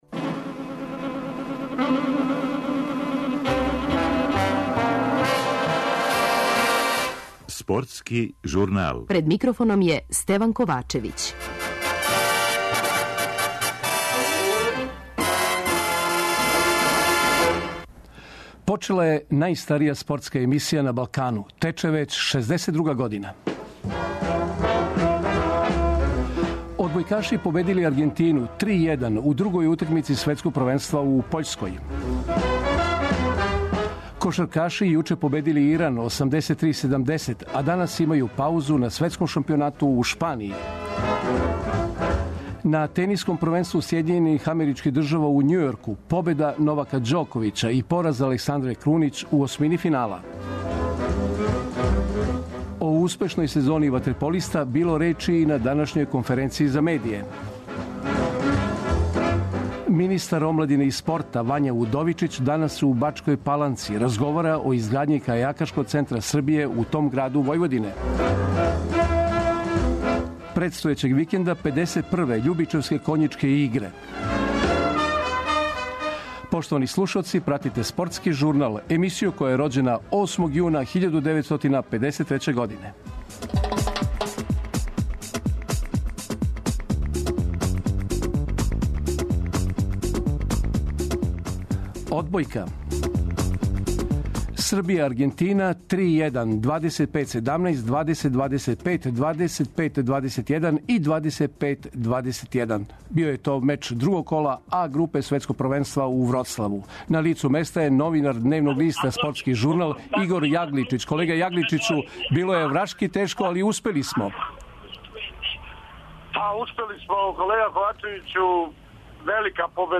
Ослушнућемо атмосферу у редовима наших одбојкаша после утакмице са Аргентином, у Вроцлаву на Светском првенству.
О свему, у ексклузивним изјавама за Радио Београд, говориће и Ђоковић и Крунићева.